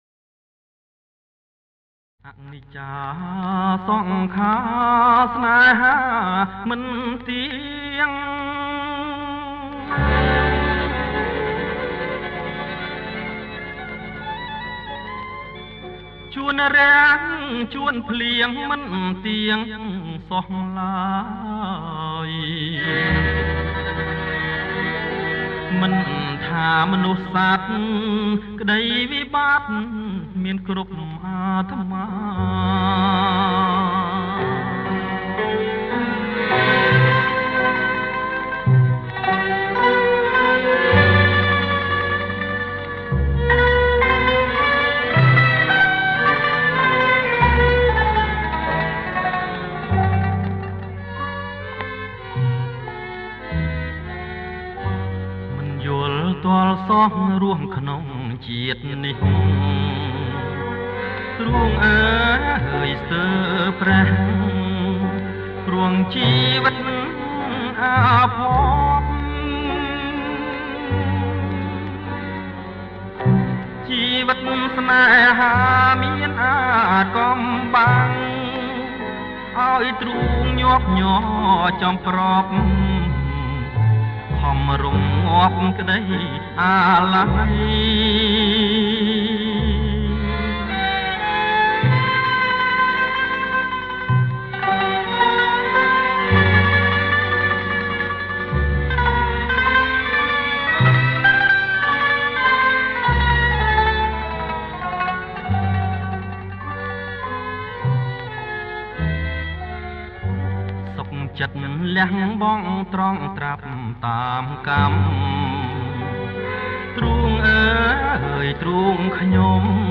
ប្រគំជាចង្វាក់ BLUE